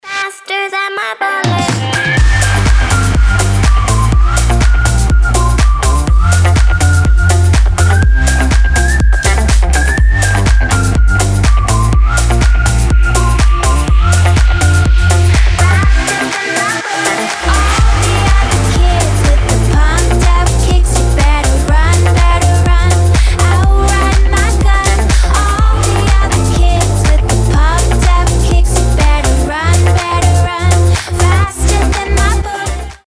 • Качество: 320, Stereo
позитивные
свист
женский вокал
deep house
dance